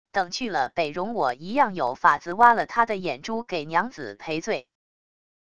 等去了北戎我一样有法子挖了他的眼珠给娘子赔罪wav音频生成系统WAV Audio Player